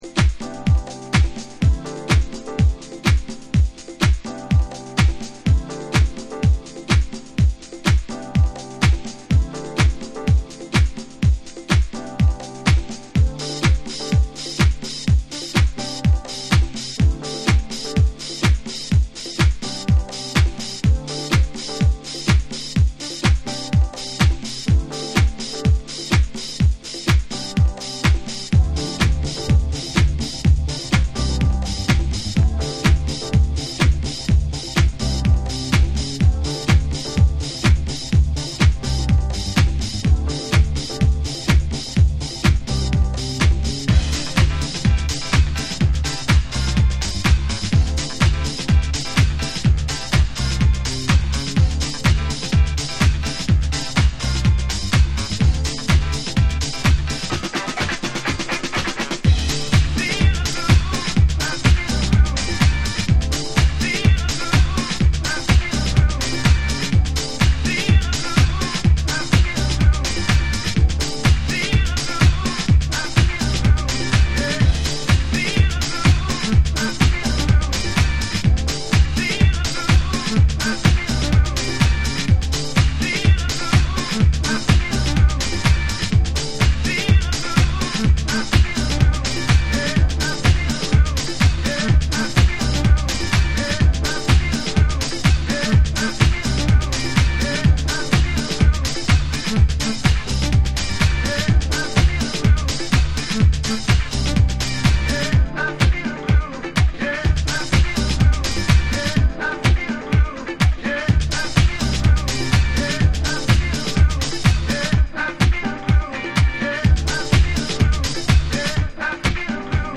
頭から尻尾までドントストップなグルーヴキープ力のあるサンプリングハウス。サンプリング粒子飛び散るグレイトディスコハウス。